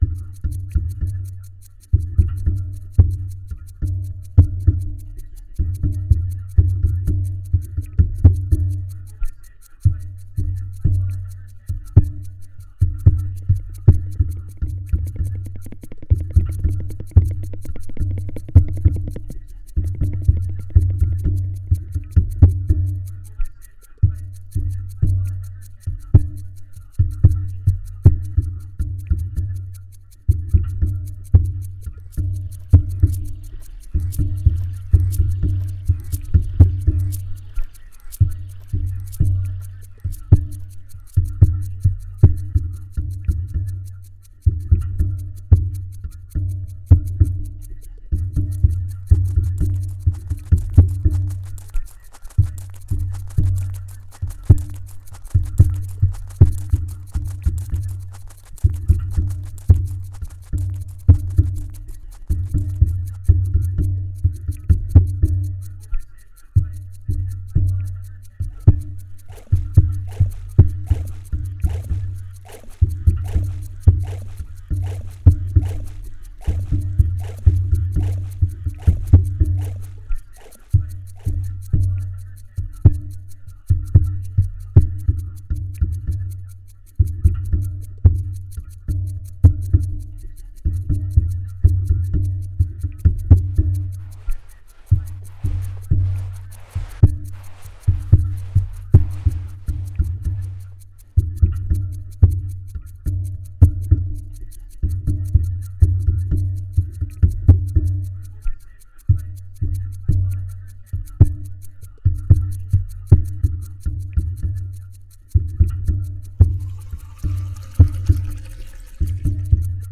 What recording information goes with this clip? In the sound workshop, the participants worked with recorded sound and the task was to create some relatively short ‘soundscapes’ that worked with parameters other than musical. Various microphones, sound sources and varying spaces were experimented with.